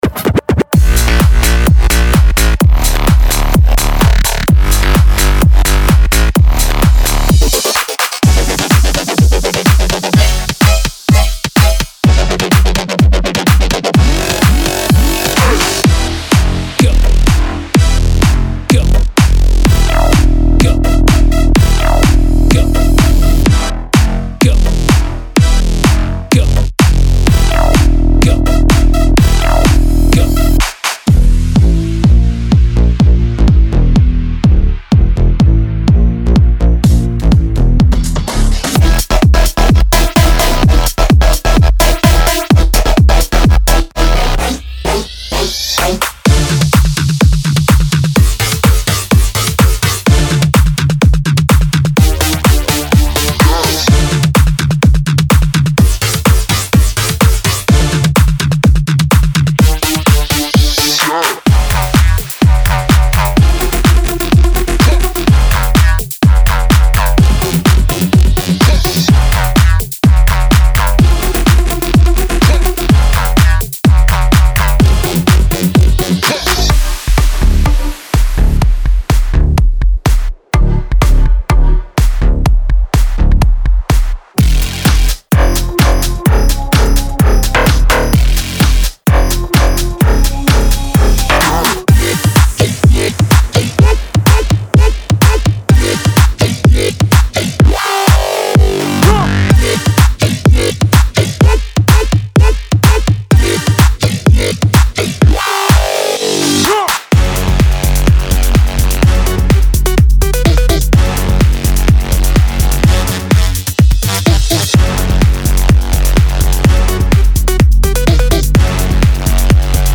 Type: Spire Serum Midi Samples
Electro House House
Basses, Drums, Percussion, Synths, FX, Vocals
126 BPM